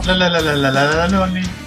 PLAY batida na porta
Play, download and share Bati original sound button!!!!